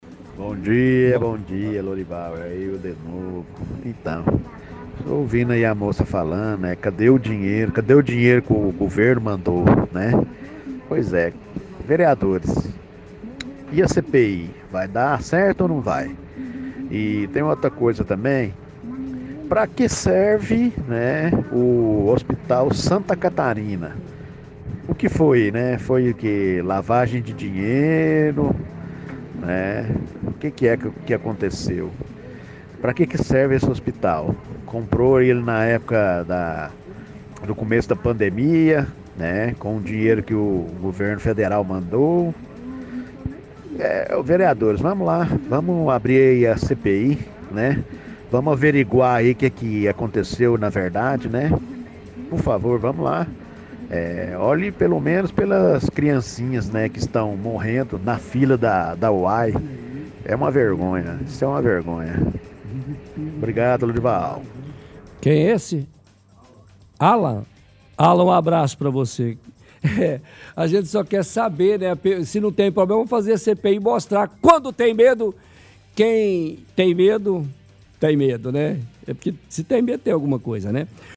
– Outro ouvinte questiona pra que serve o hospital Santa Catarina, questionando se é lavagem de dinheiro.